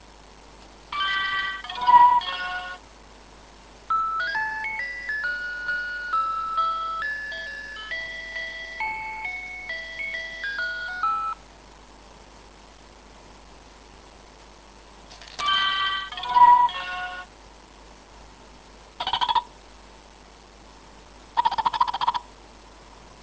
Voilà mon joujou Papillon que le Pôpa Nowel m'a apporté : il fait de la musique et des lumières
jouetpapillon.wav